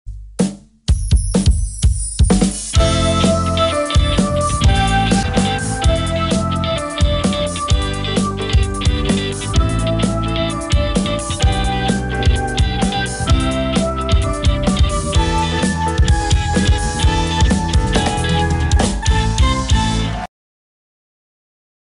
nhac-nen-tro-choi-am-nhac.wav